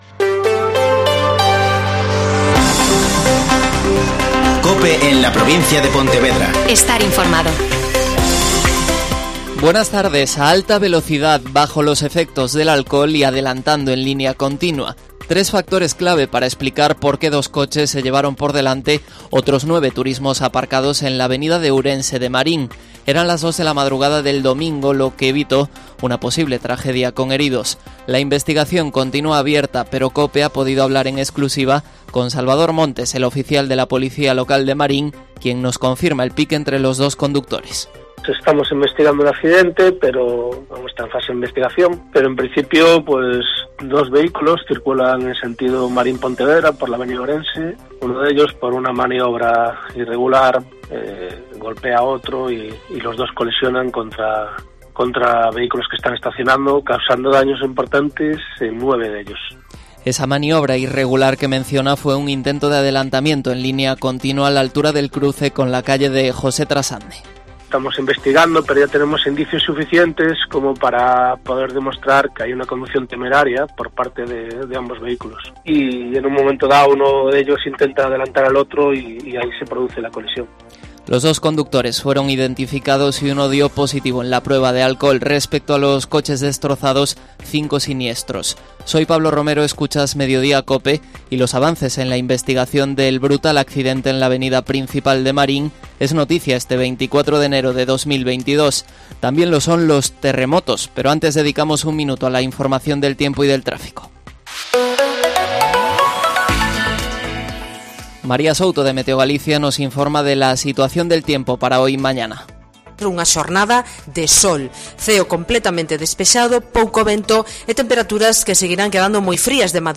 Mediodía COPE en la Provincia de Pontevedra (Informativo 14:20h)